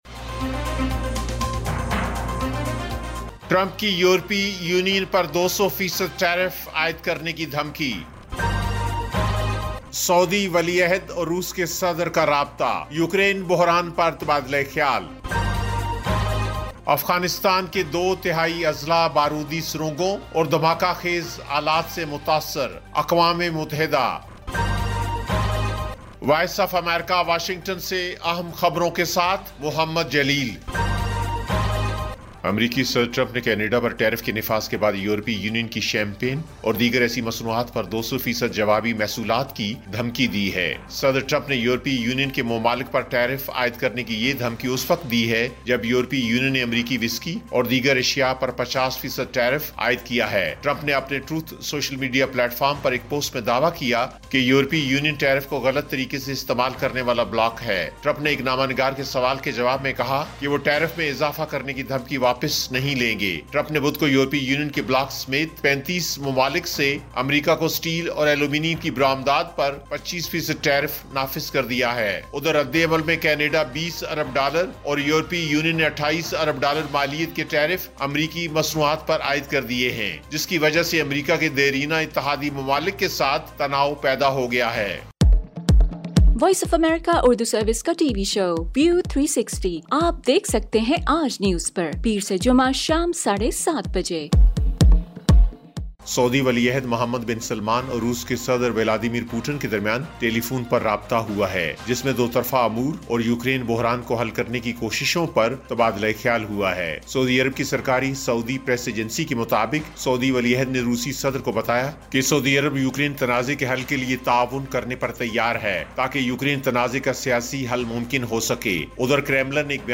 ایف ایم ریڈیو نیوز بلیٹن: شام 7 بجے